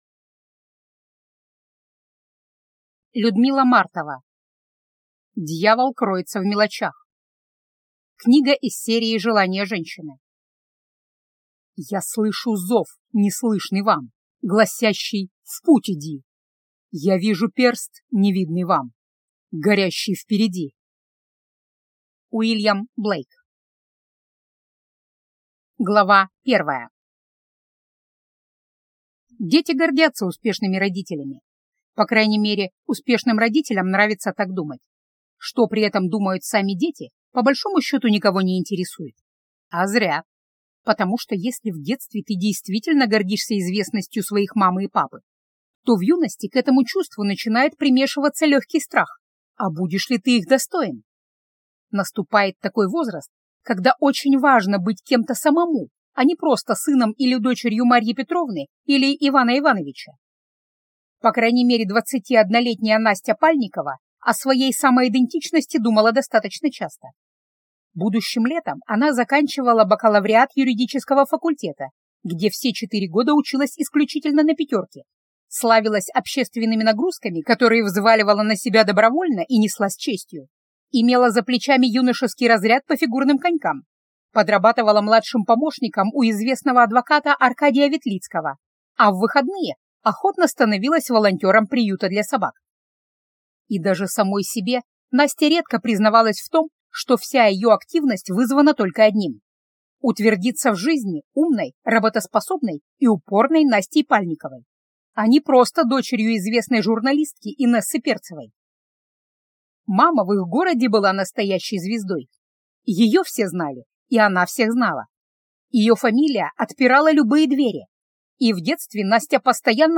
Аудиокнига Дьявол кроется в мелочах | Библиотека аудиокниг